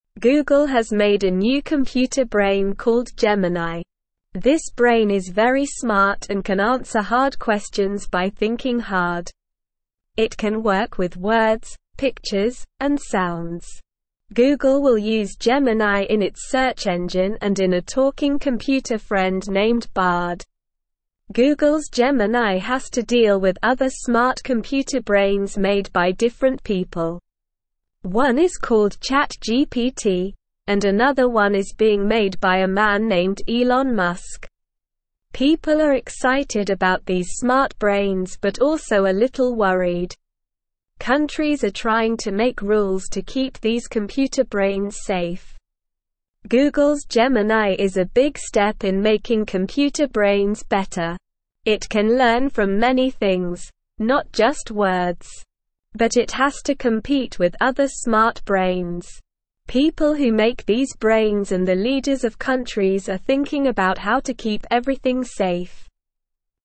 Slow
English-Newsroom-Lower-Intermediate-SLOW-Reading-Googles-Smart-Computer-Friend-Gemini-Can-Answer-Questions.mp3